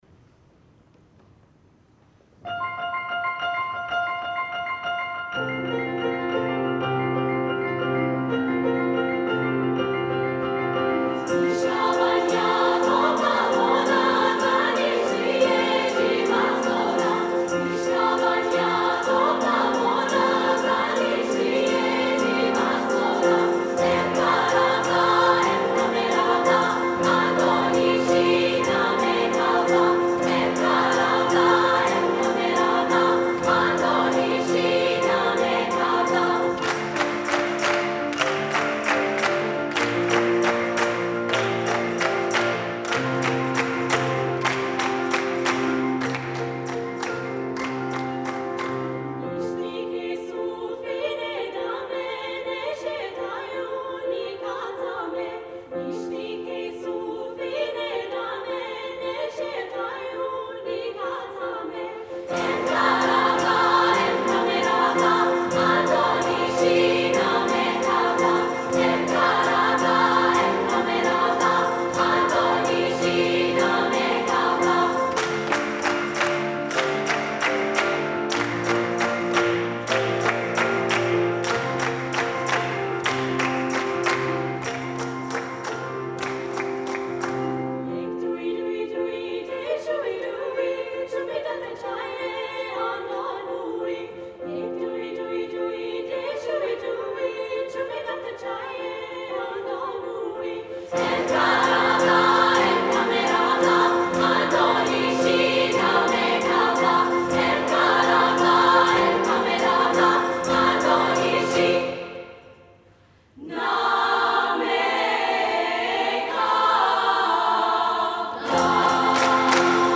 Recordings from The Big Sing National Final.
Bel Canto Burnside High School Niska Banja Loading the player ...